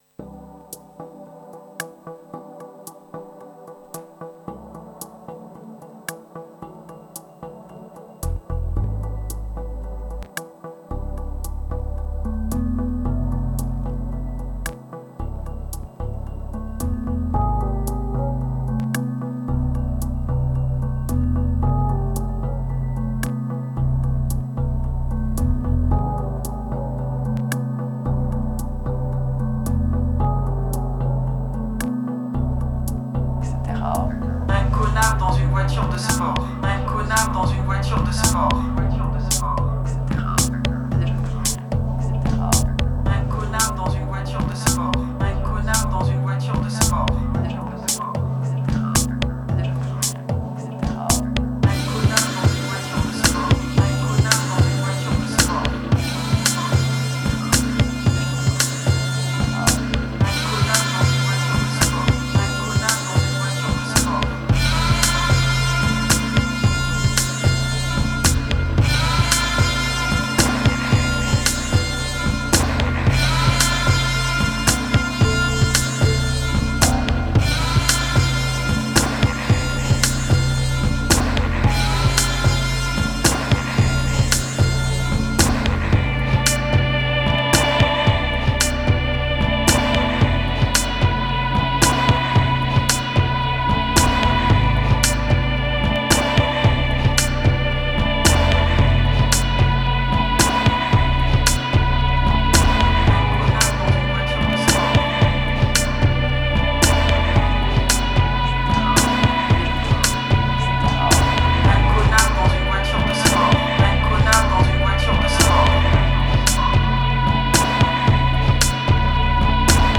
504📈 - 84%🤔 - 56BPM🔊 - 2021-10-10📅 - 429🌟